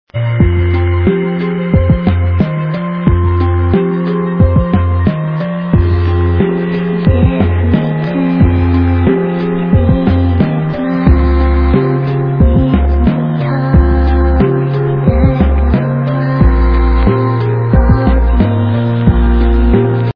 sledovat novinky v oddělení Experimentální hudba